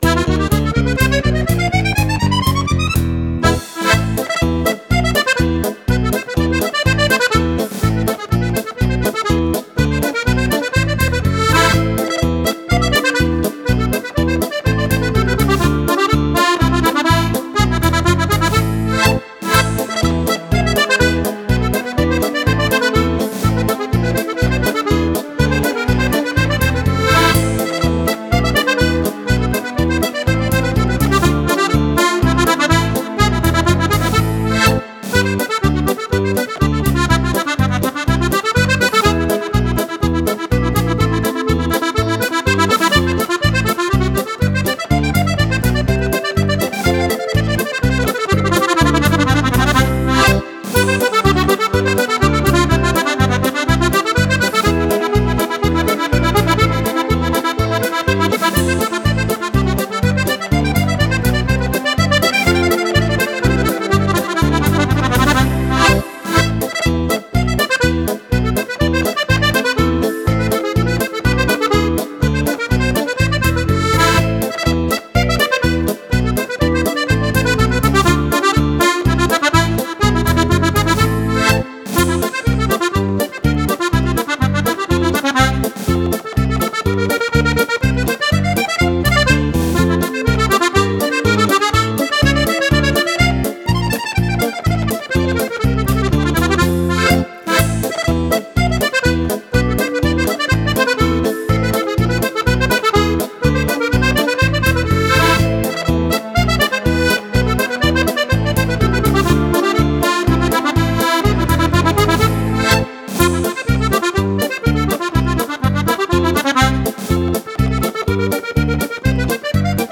Polca per Fisarmonica